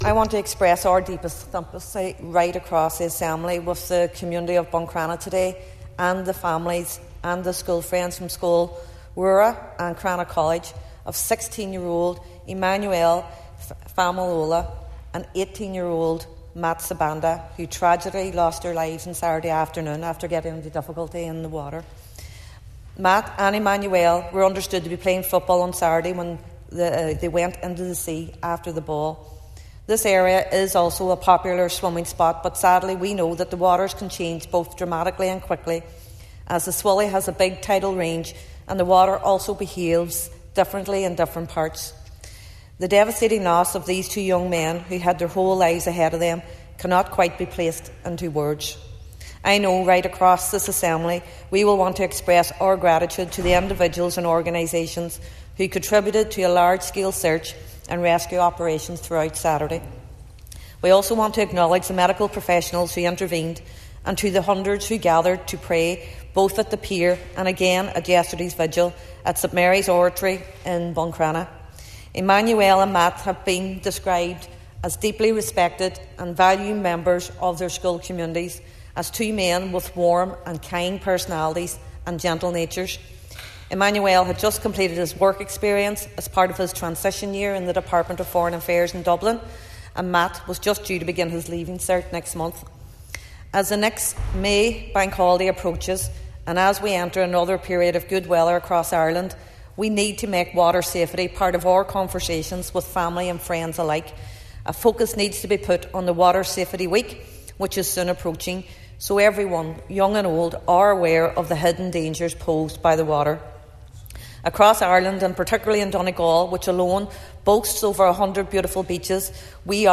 During a short debate in the chamber at Stormont, Foyle MLA Ciara Ferguson called for a renewed focus on water safety, and told the people of Buncrana and Inishowen that their pain is shared by their neighbours in Derry, and further afield………..